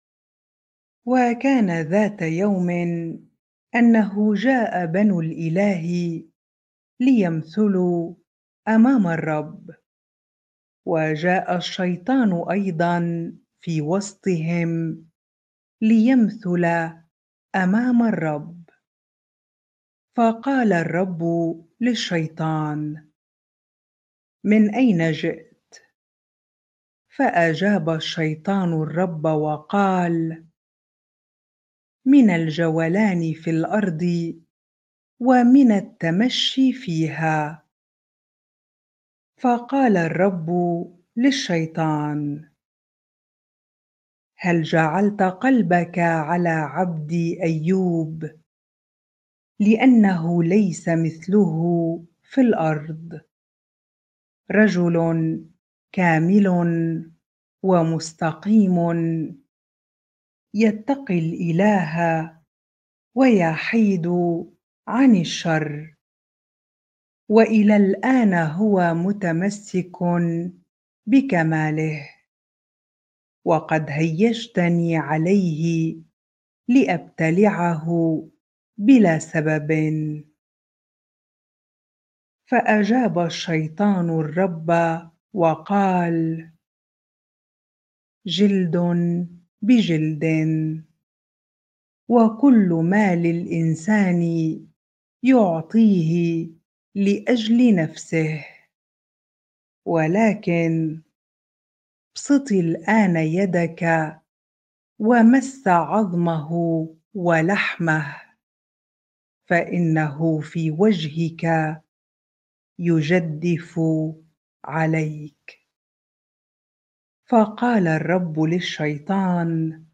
bible-reading-Job 2 ar